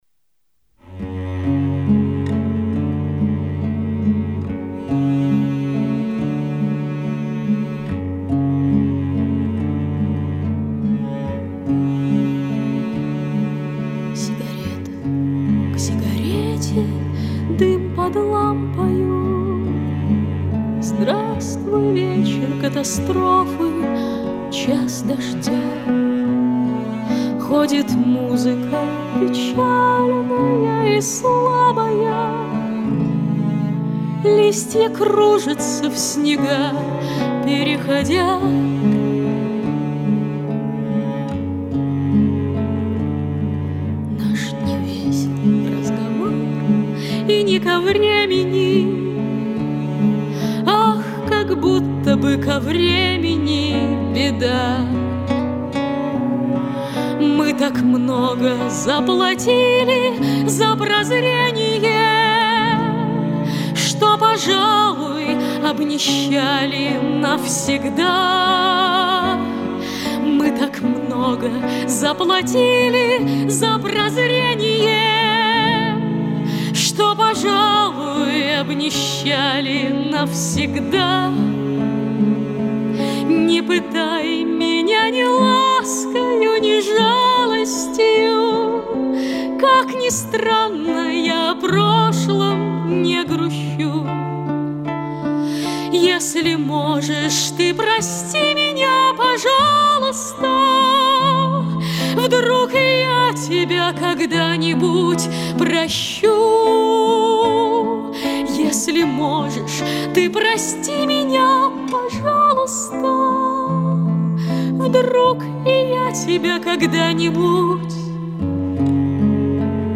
Авторская песня